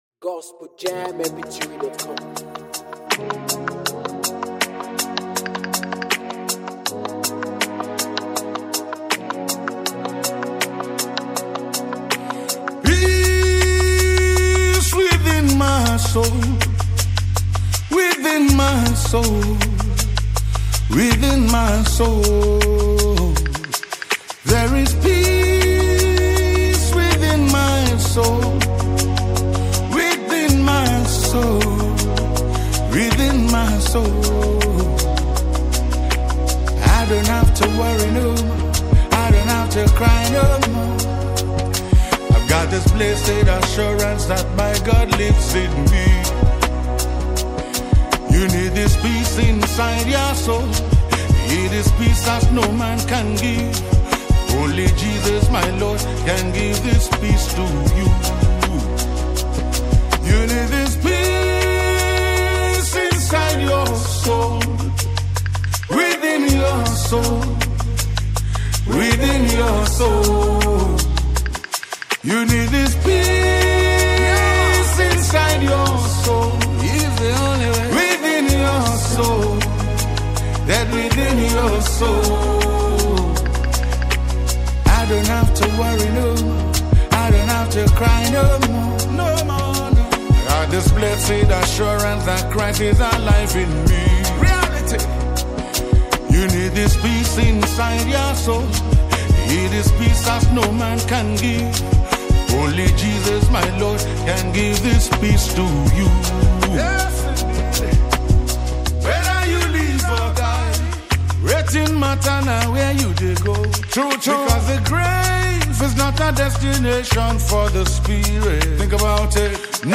African praisemusic